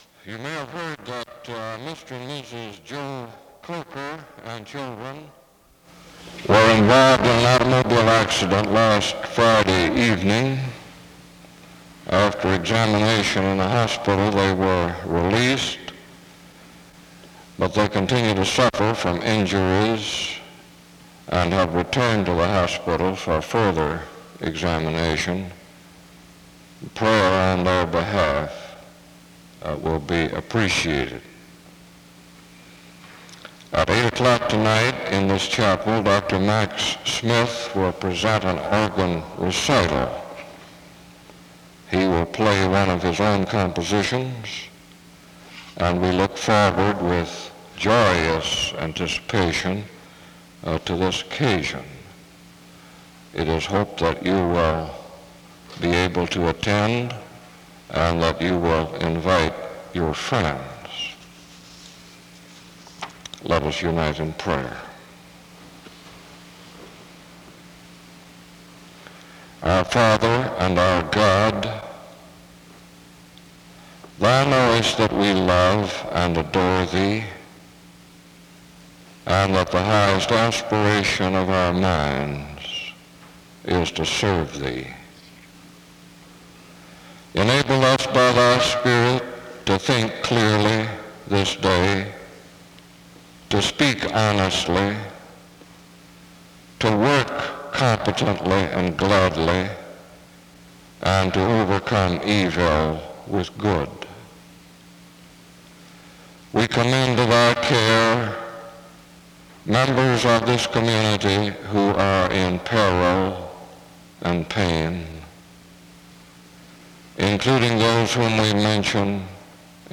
SEBTS Chapel
Ephesians 4:25-32 is read from 4:25-6:16.
In Collection: SEBTS Chapel and Special Event Recordings SEBTS Chapel and Special Event Recordings